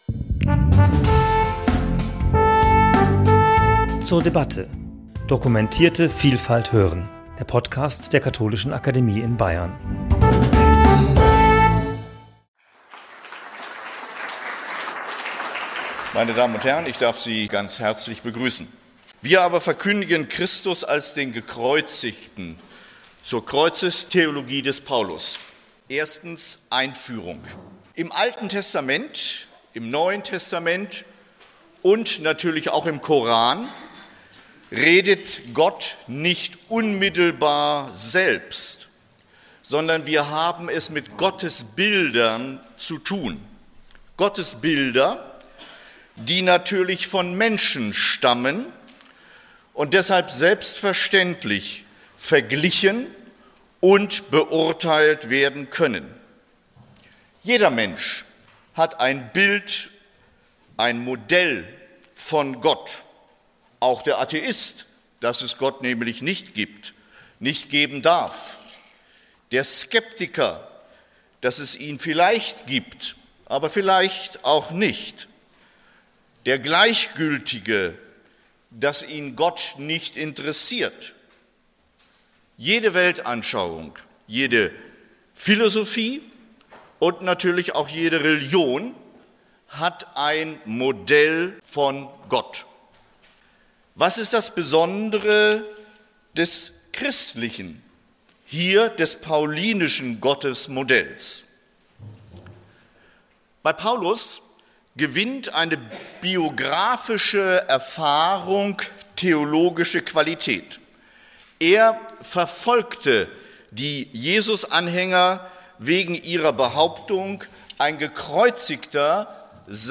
Bei den Biblischen Tagen, 21. bis 23. März 2016, zum ersten Korintherbrief referierte